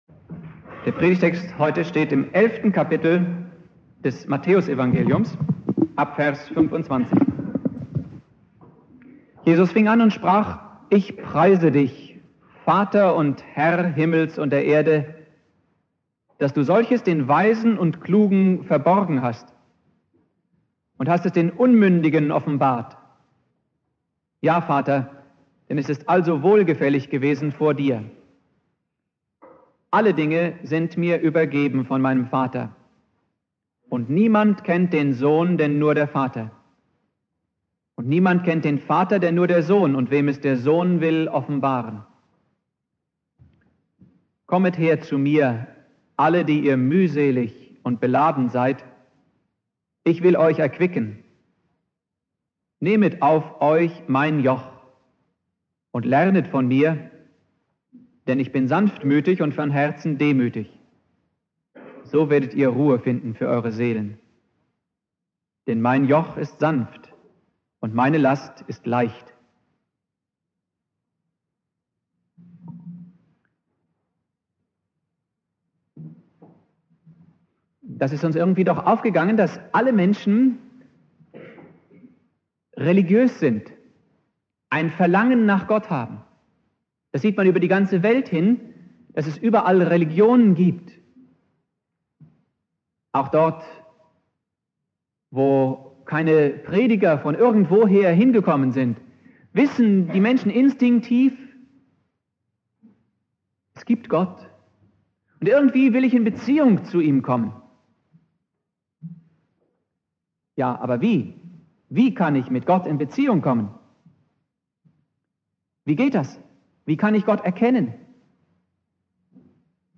(erste Aufnahme mit Tonabnehmer, deshalb bessere Aufnahmequalität)